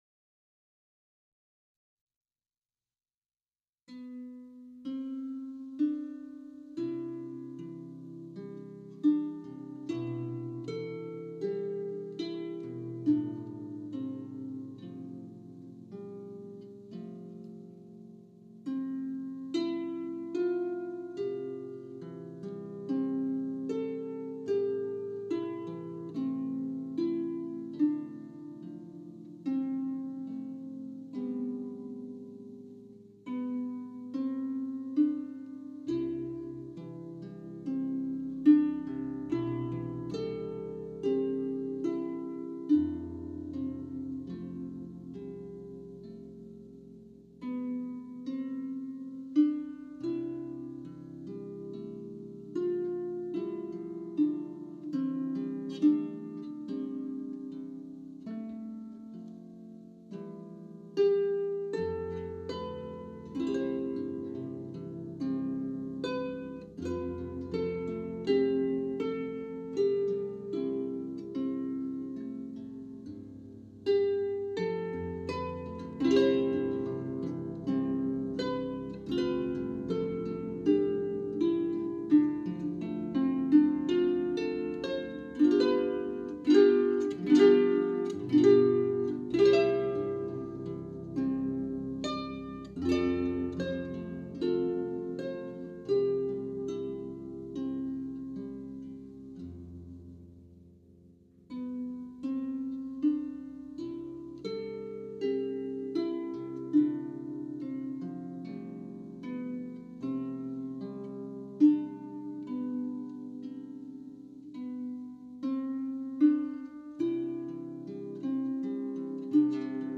Palm Springs Harpist 1
Palm-Springs-Harpist-1-Danny-Boy.mp3